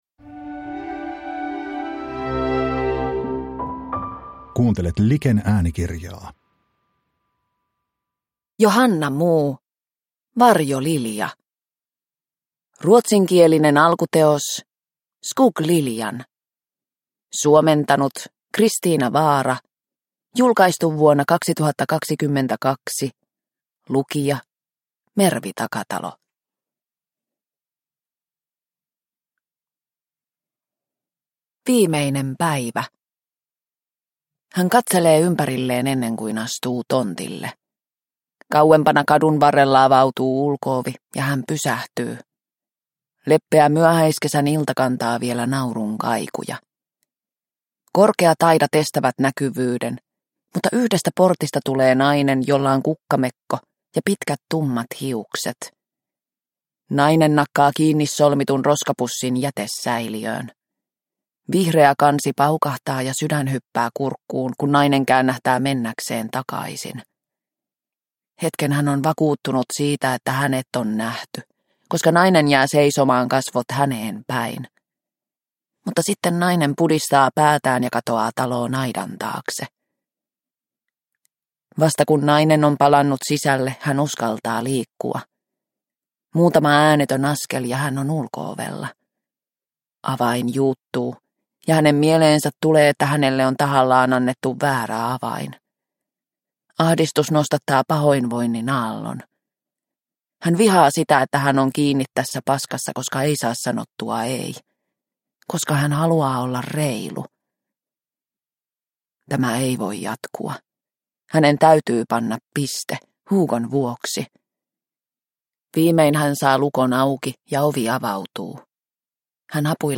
Varjolilja – Ljudbok – Laddas ner